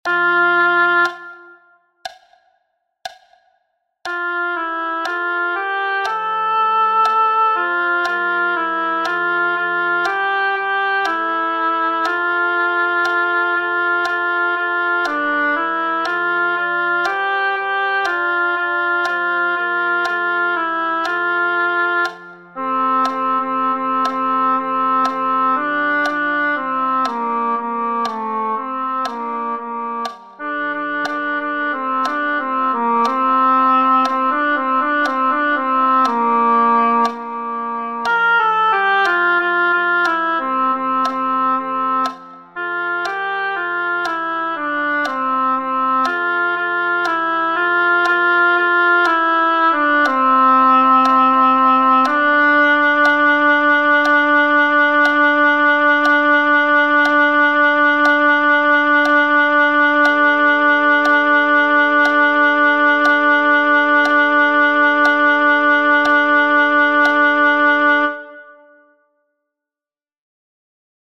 soprani - for virtual choir